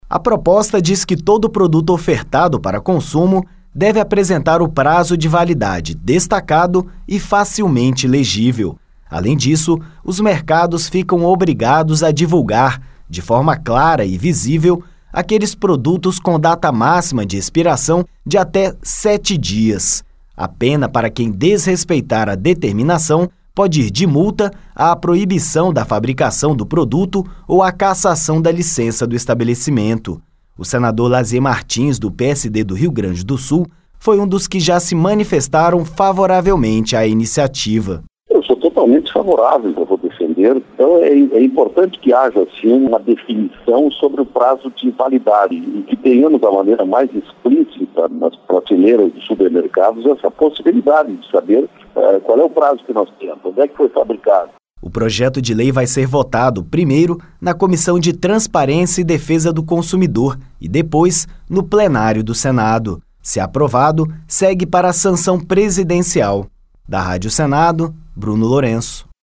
O senador Lasier Martins (PSD-RS) afirmou que é importante ter essa informação de maneira mais explícita nas prateleiras.